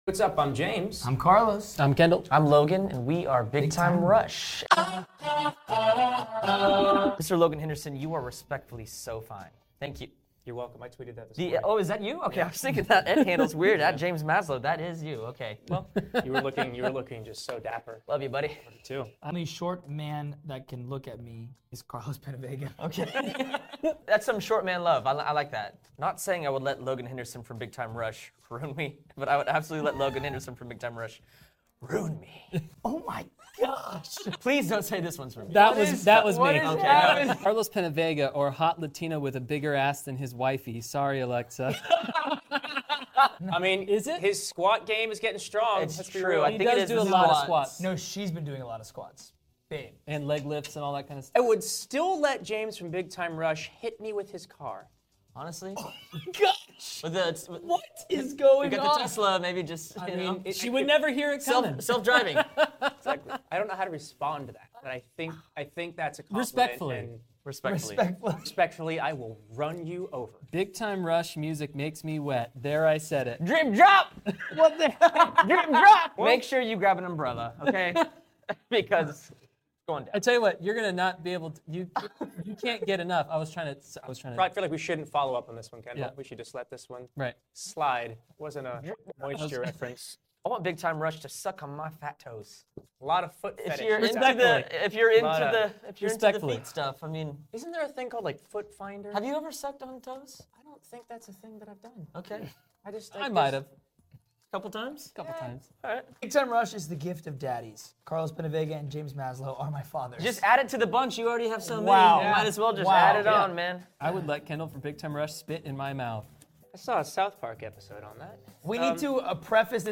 I love this interview.